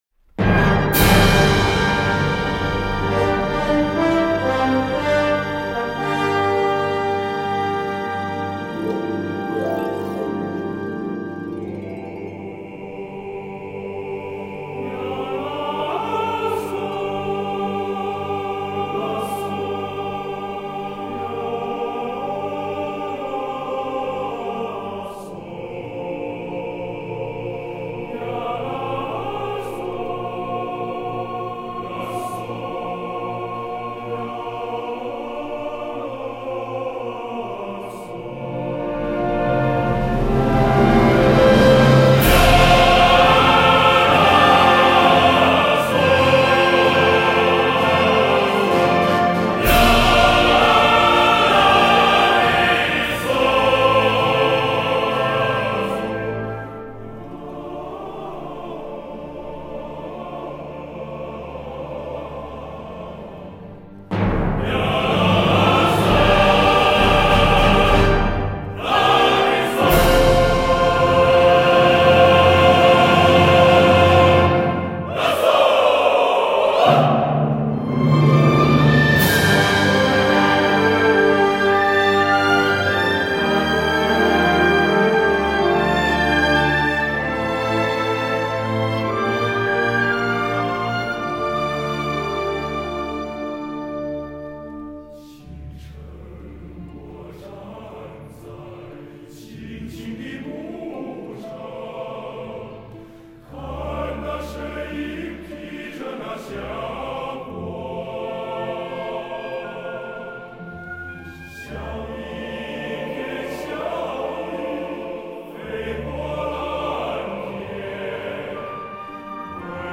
前所未有的中国全男声合唱大碟
现场再现，声声浪潮铺天盖地！
一张真正考验音响动态极限的大碟